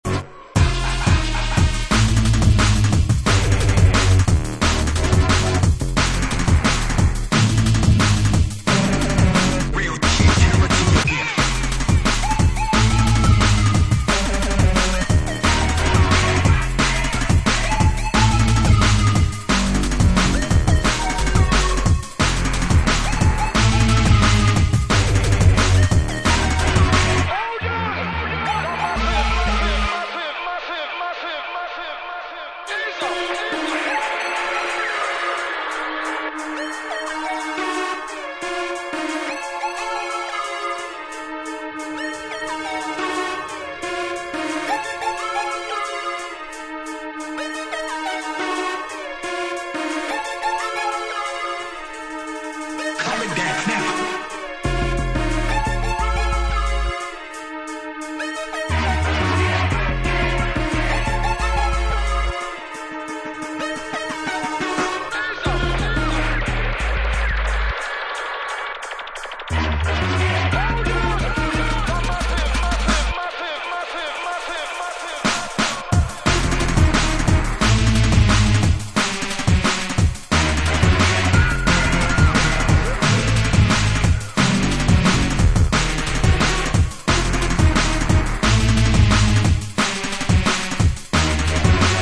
Genre: Drum And Bass